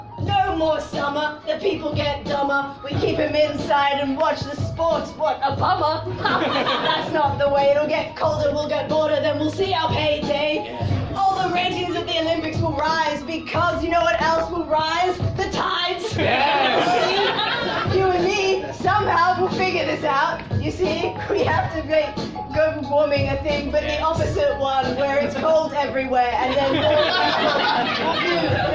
Tags: rap